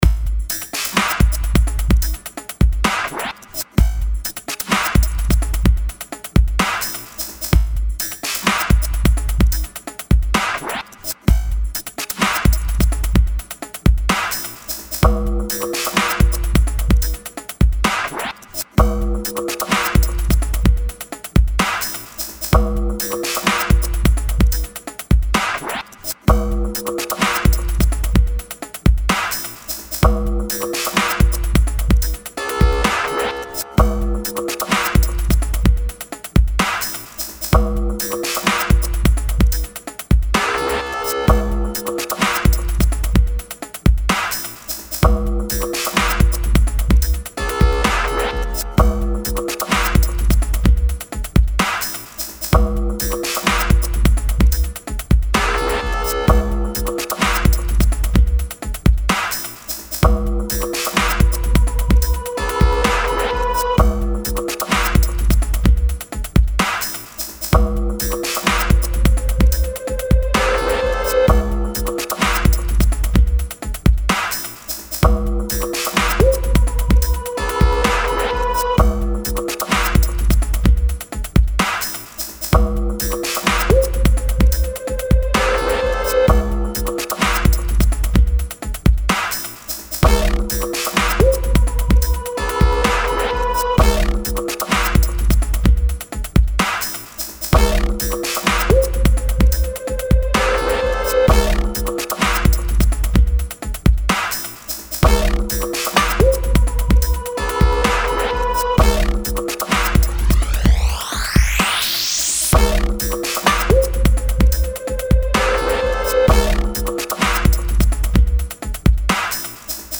Breakbeat